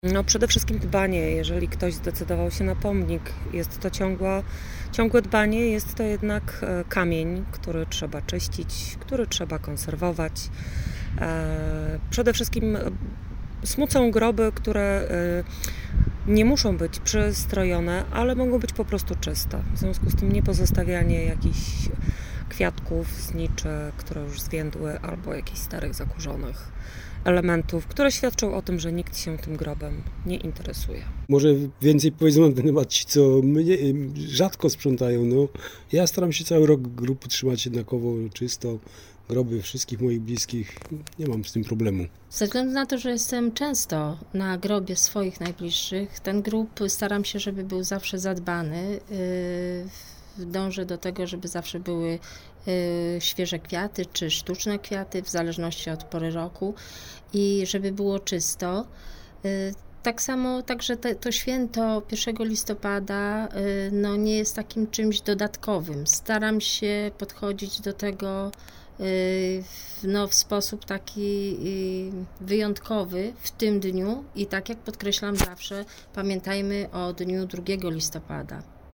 Zaglądamy na parafialny cmentarz św. Jacka na wrocławskich Swojczycach, pytając wrocławianki i wrocławian o to jak zmieniają się cmentarze.